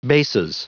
Prononciation du mot bases en anglais (fichier audio)
Prononciation du mot : bases